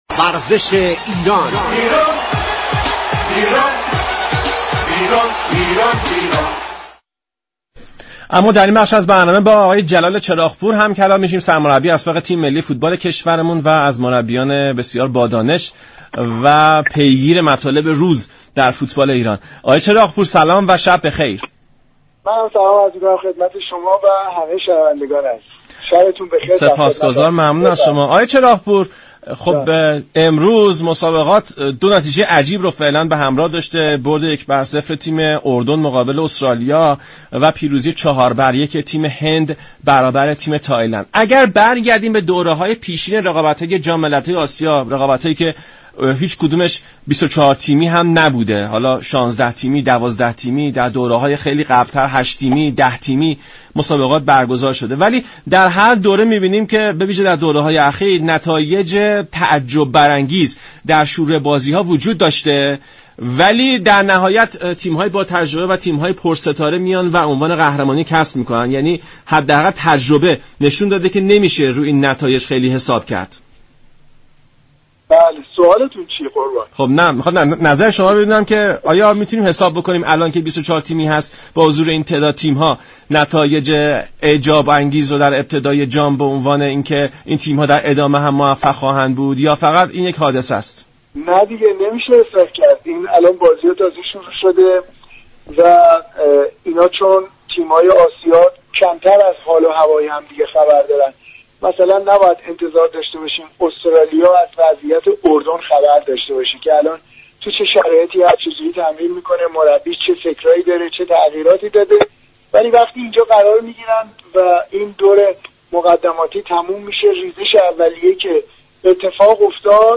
سرمربی اسبق تیم ملی ایران در گفت و گو با رادیو ایران گفت.